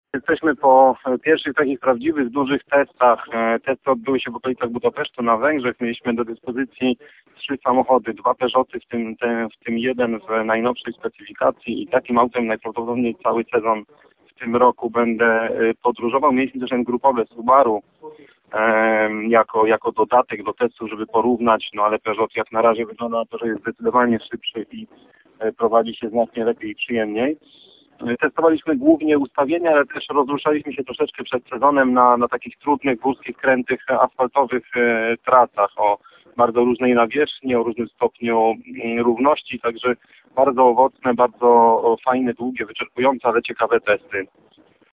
(Wywiad)_O_testach_przed_sezonem.mp3